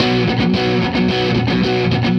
AM_HeroGuitar_110-B02.wav